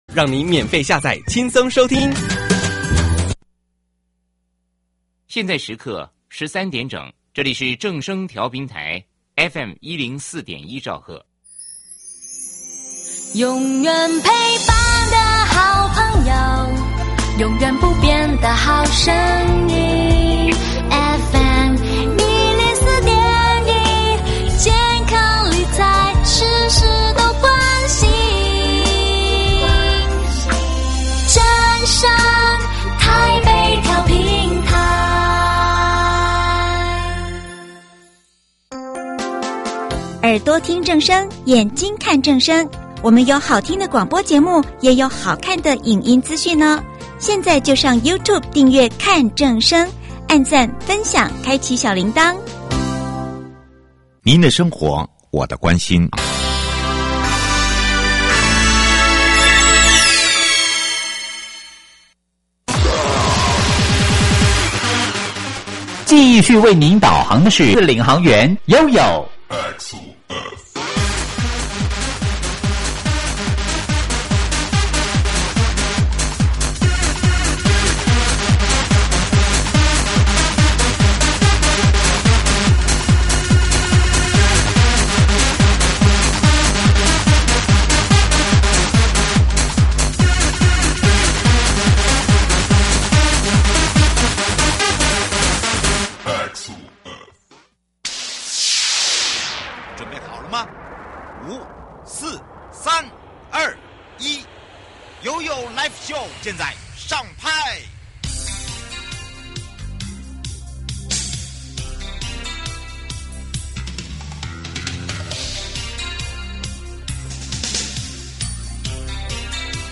今天，我們就邀請到最清楚基隆道路變化的人─工務處簡翊哲處長，一起來聊聊基隆城市環境升級的「前世今生」，以及我們明年還會看到什麼新的改變。